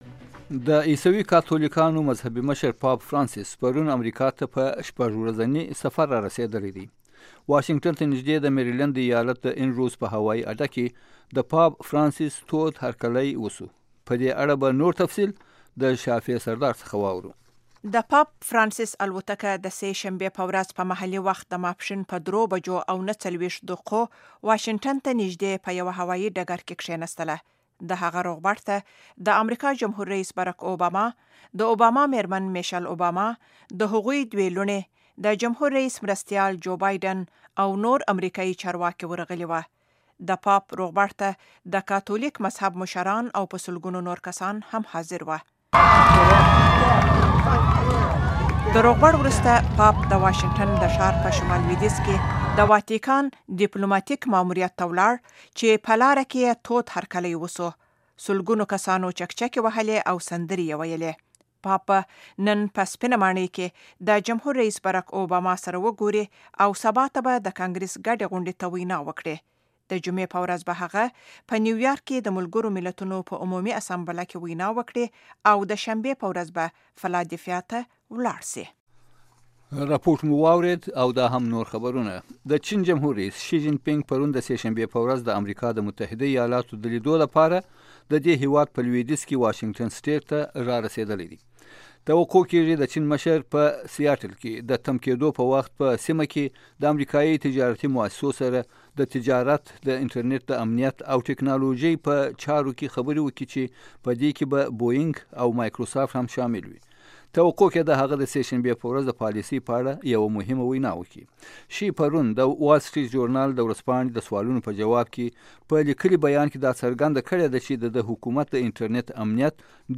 د افغانستان او نړۍ تازه خبرونه په ۳ دقیقو کې